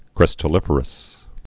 (krĭstə-lĭfər-əs) also crys·tal·lig·er·ous (-lĭj-)